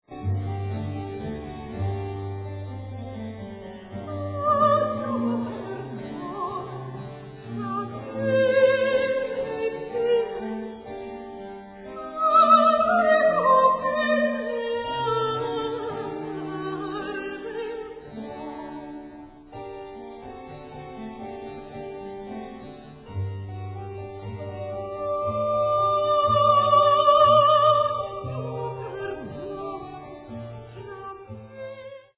soprano
cello